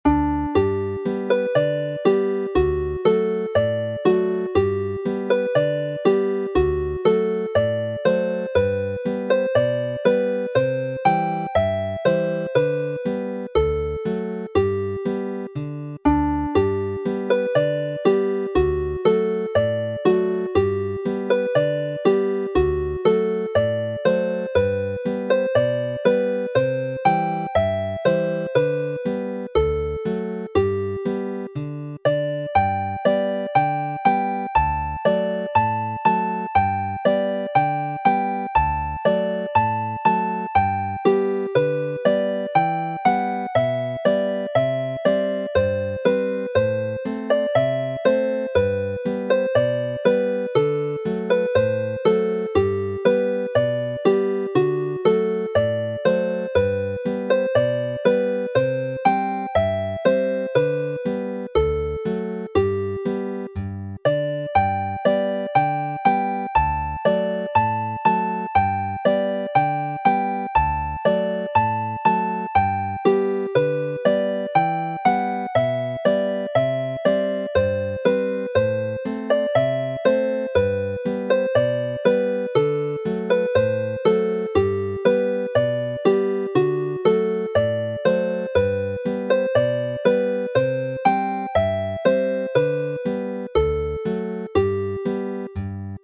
Chwarae'n araf
Play slowly